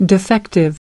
Transcription and pronunciation of the word "defective" in British and American variants.